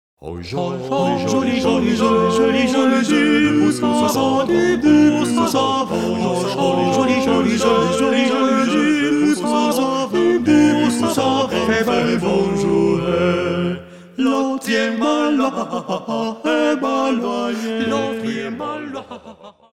madrigals composed during the Renaissance
This is vocal music that belongs to the soul.